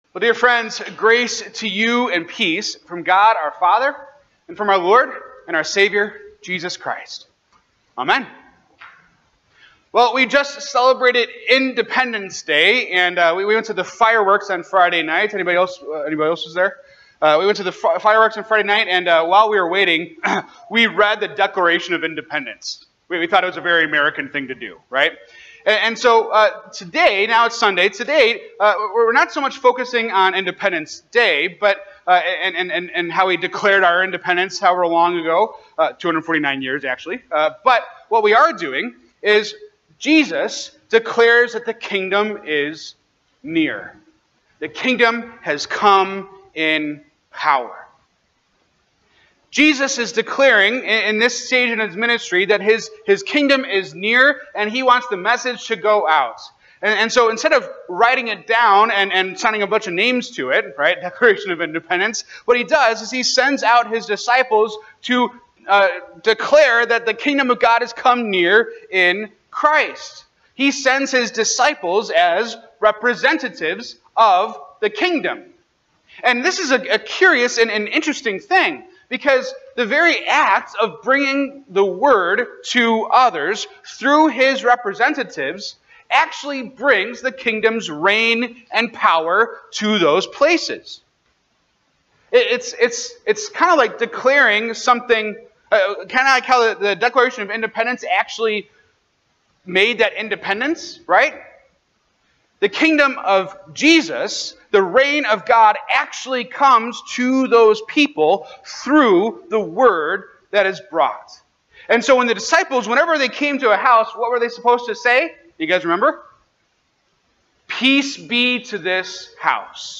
This sermon on Luke 10:1-20 focuses on Jesus's proclamation that the Kingdom of God is near. It explores how disciples, then and now, are sent as representatives to declare this truth, bringing God's reign and power through the Word.